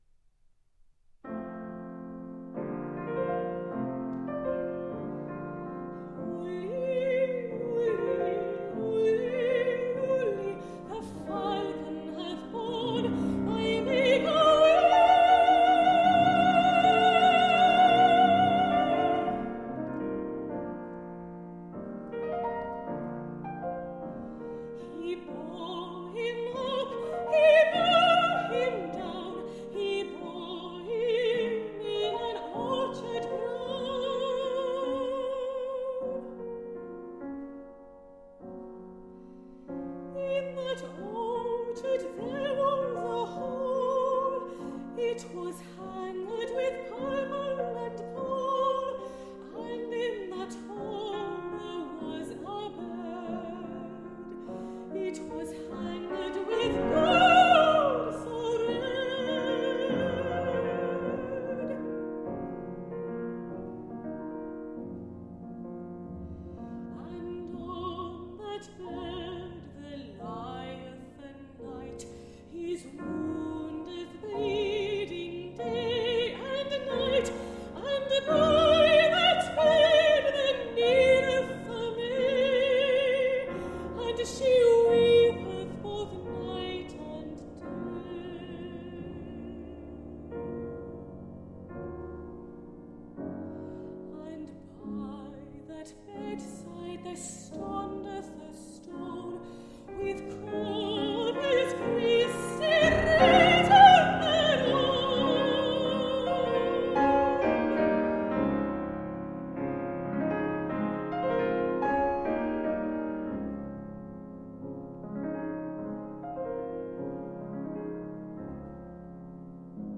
Songs for high voice and pianoforte.
These songs can be sung by a baritone or mezzo-soprano.